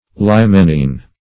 Search Result for " limenean" : The Collaborative International Dictionary of English v.0.48: Limenean \Li*men"e*an\ (l[-e]*m[e^]n"[-e]*an), a. Of or pertaining to Lima, or to the inhabitants of Lima, in Peru.